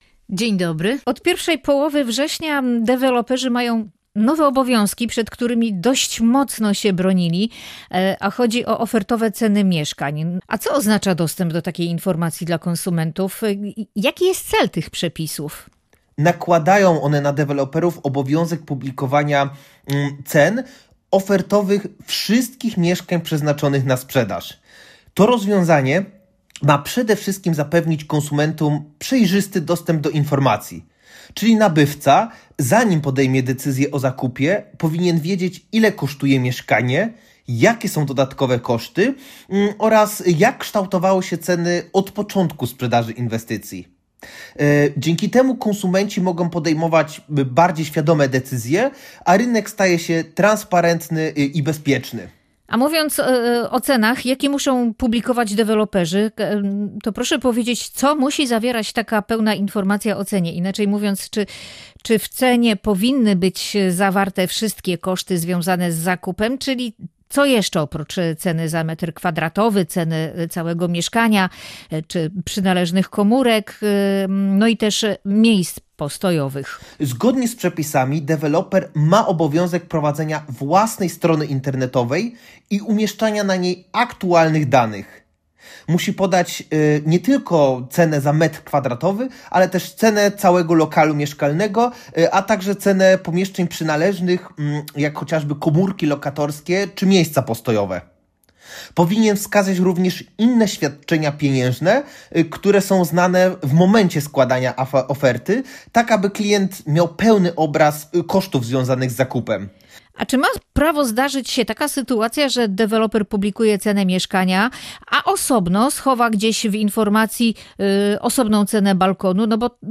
W rozmowie poruszono również kwestię integralności ceny mieszkania, wyjaśniając, że balkon nie może być wyceniany osobno.